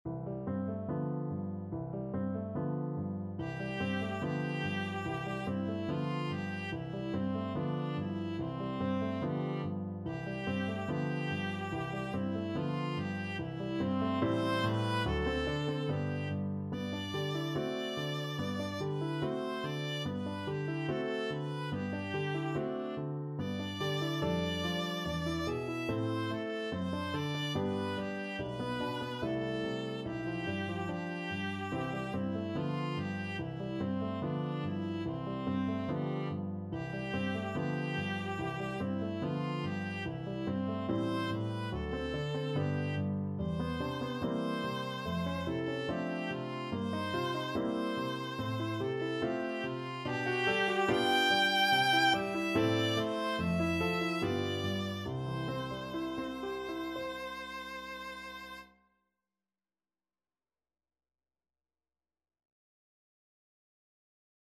2/4 (View more 2/4 Music)
Allegretto moderato =72
Classical (View more Classical Viola Music)